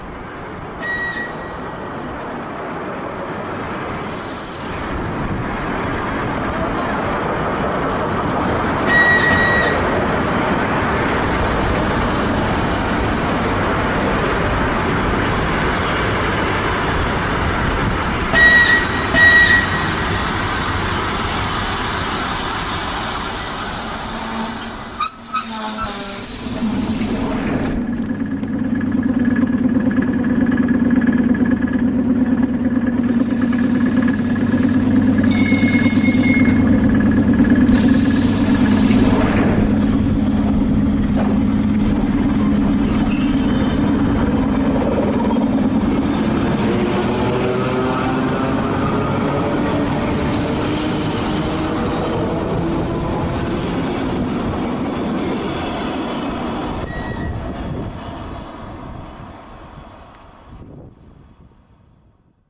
Звуки дверей поезда
Звук остановки электрички, высадка и посадка пассажиров, затем движение